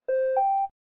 connection_started_sepura.mp3